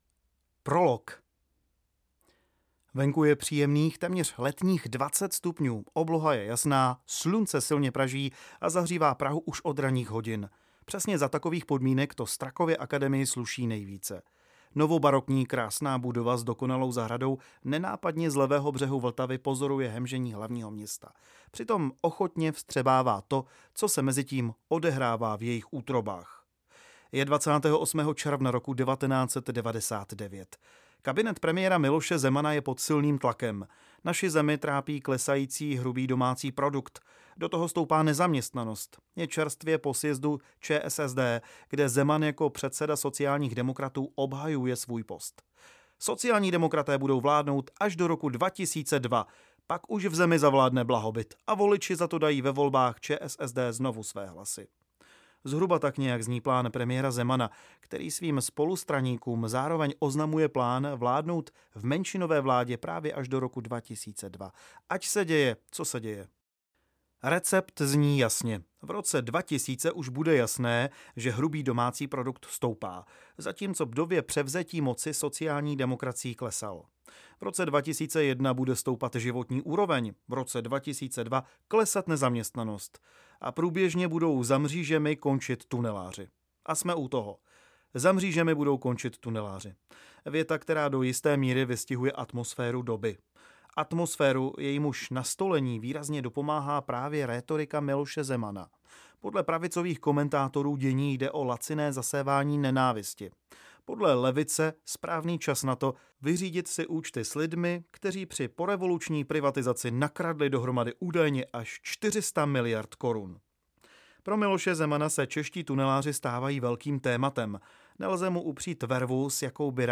Jak se těží miliardy? audiokniha
Ukázka z knihy